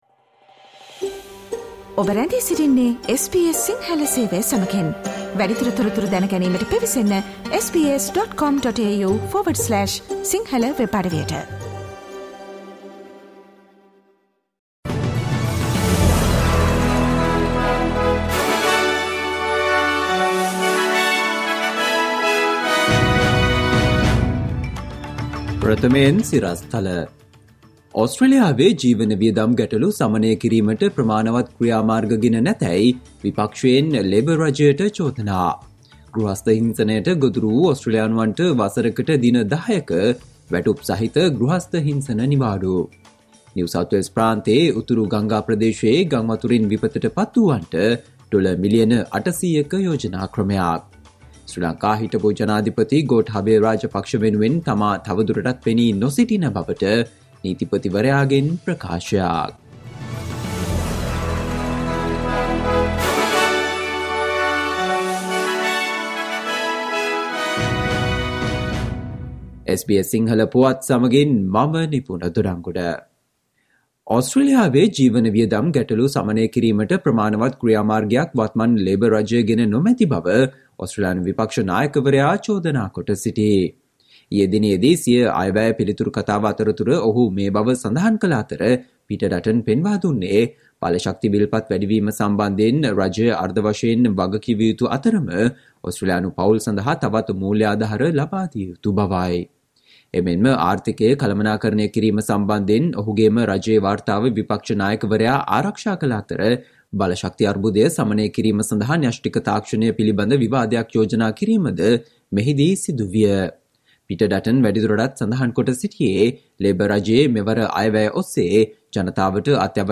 Listen to the SBS Sinhala Radio news bulletin on Friday 28 October 2022